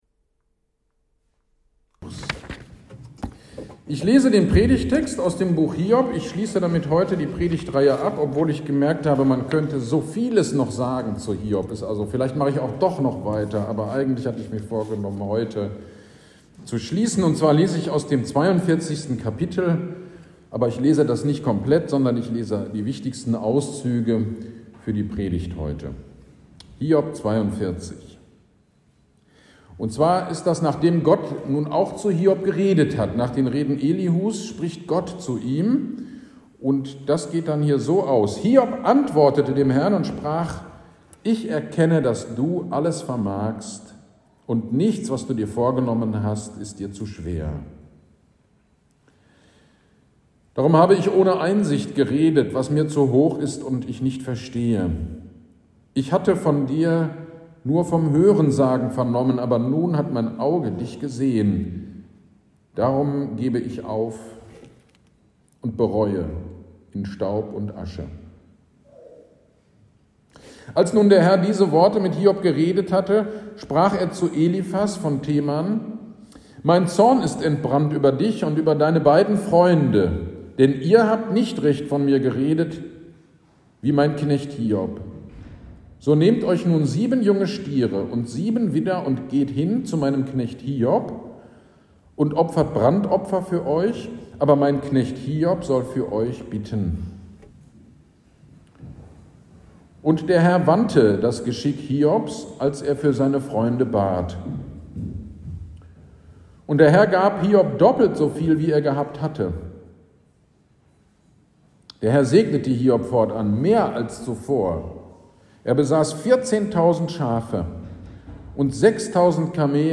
GD am 16.04.23 Predigt zu Hiob 40-42